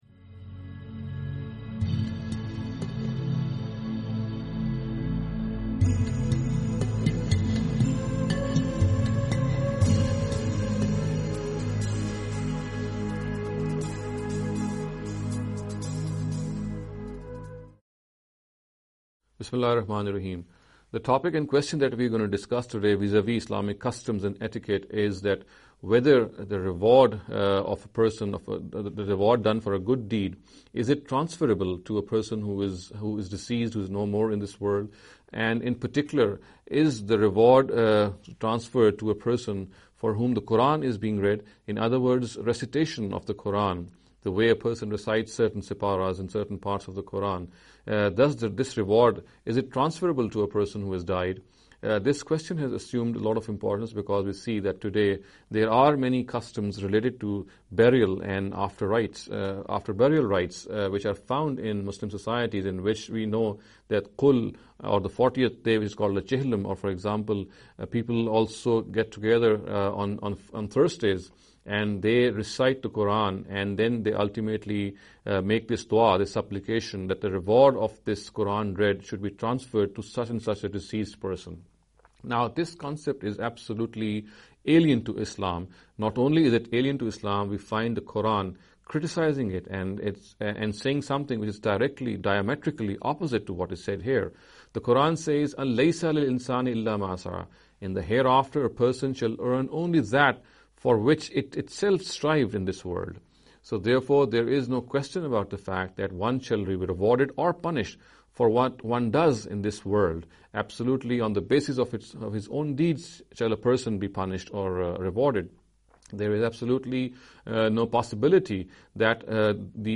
This lecture series will deal with some misconception regarding the Islamic Customs & Etiquette.